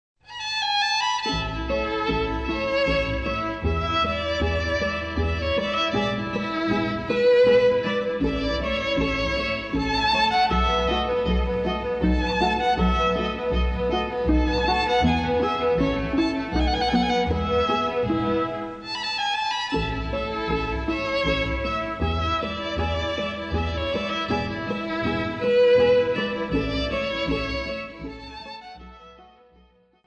minuetto.mp3